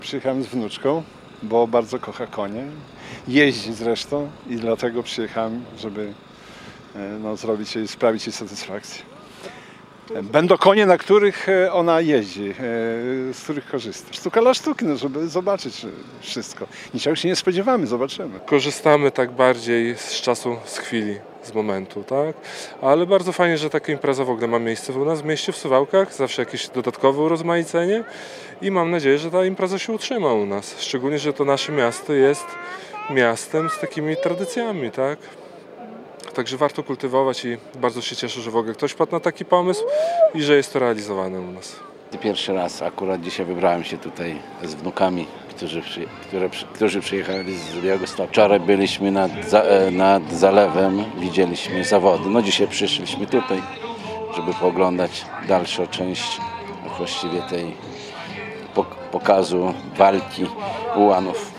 Mieszkańcy-o-pikniku-kawalerysjkim.mp3